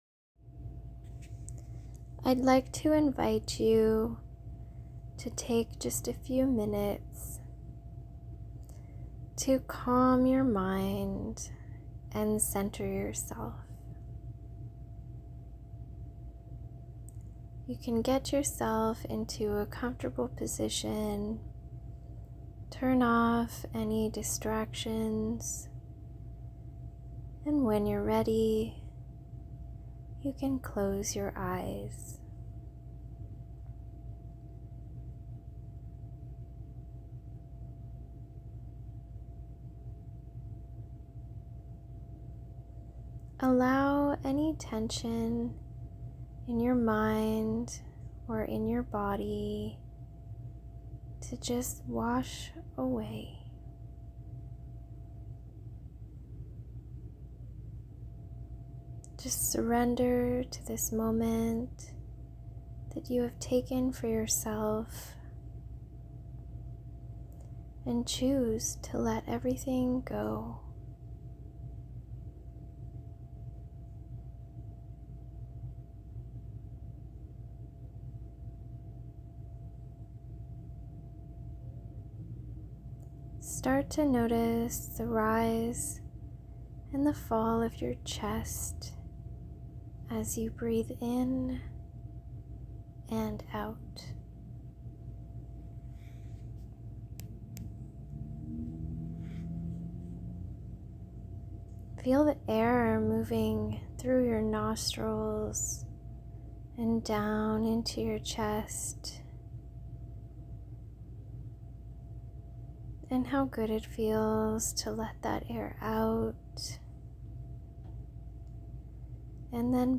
Guided Meditations
guidedmeditation.mp3